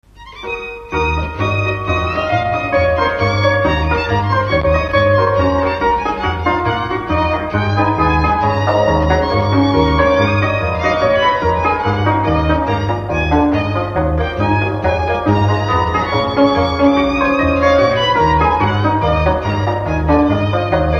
Dallampélda: Hangszeres felvétel
Dunántúl - Somogy vm. - Nagybajom
hegedű
cimbalom
bőgő
Műfaj: Ugrós
Stílus: 6. Duda-kanász mulattató stílus
Kadencia: 5 (1) 8 1